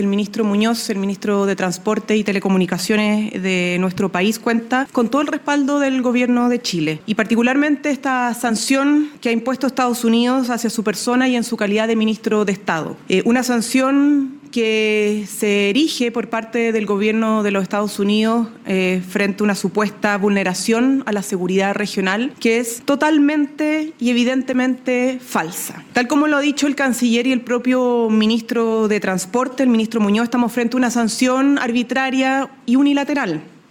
Por su parte, la ministra vocera Camila Vallejo reiteró el respaldo del Gobierno al ministro de Transportes, Juan Carlos Muñoz.